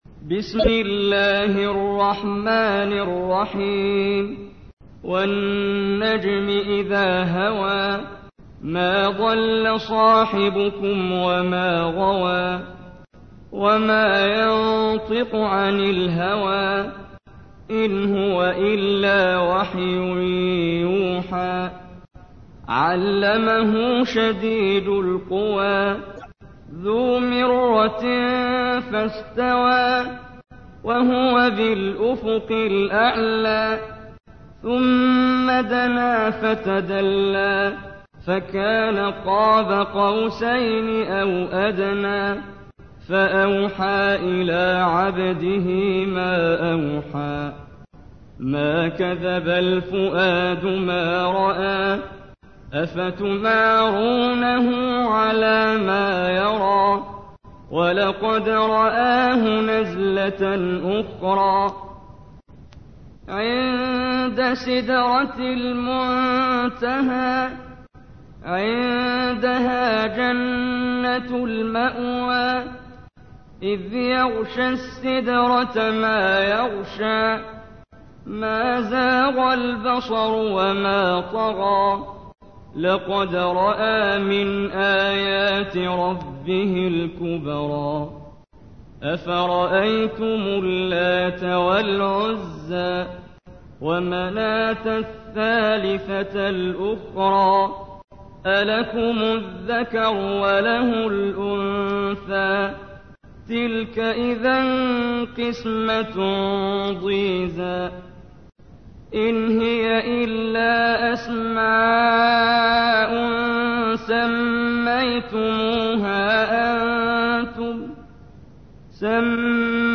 تحميل : 53. سورة النجم / القارئ محمد جبريل / القرآن الكريم / موقع يا حسين